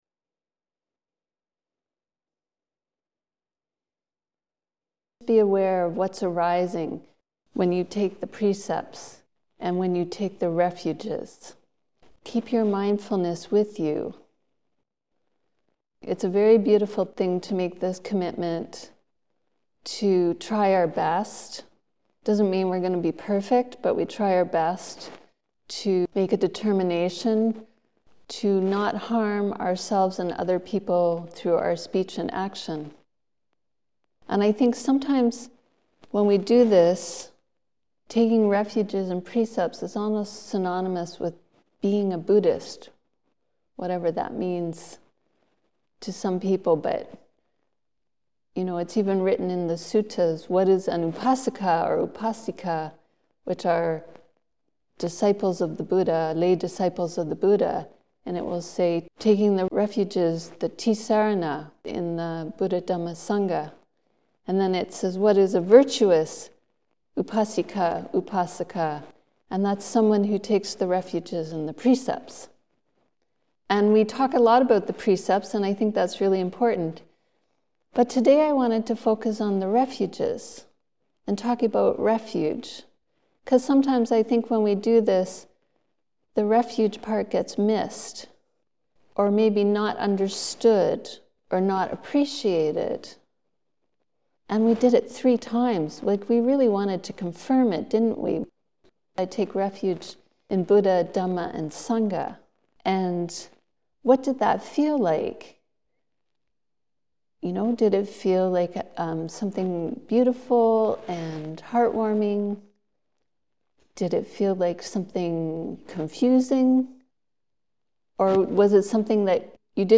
Our refuge is the awakened heart. An OBS online talk, Jan 5, 2025 https